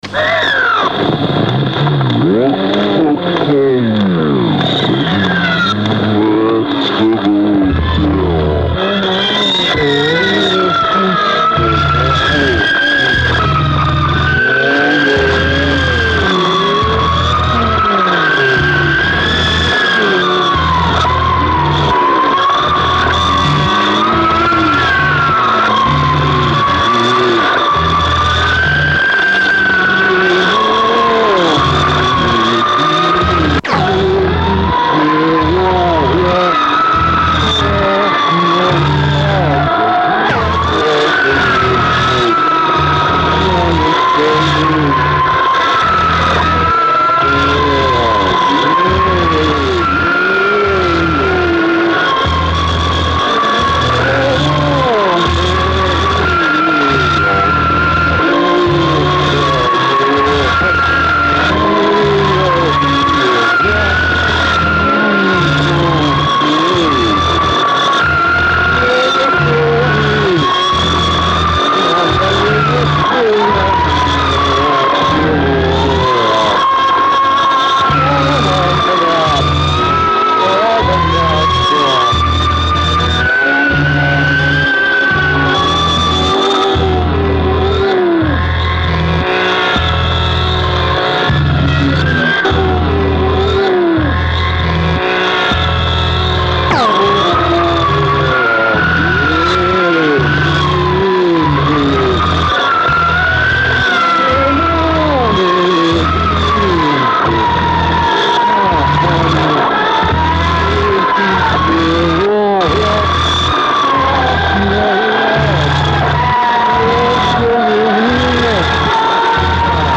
Remastered from original master tapes for superb audio.